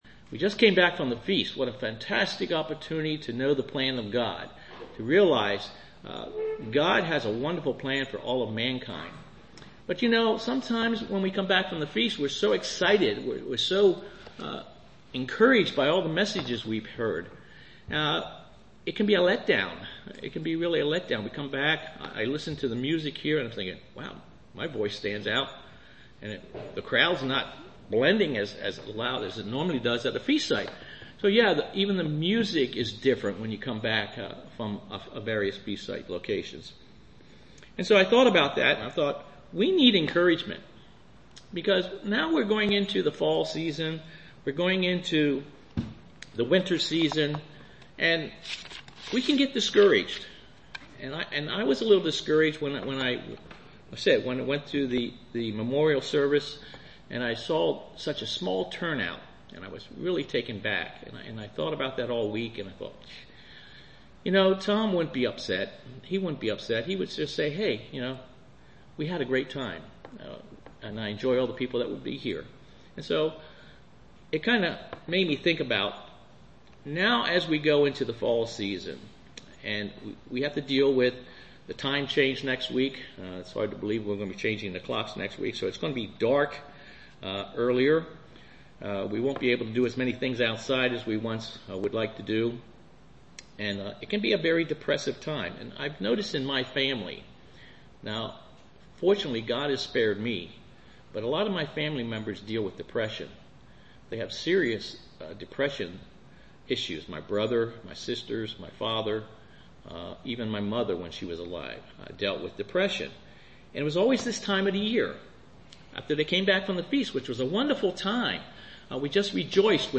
Sermons
Given in Lewistown, PA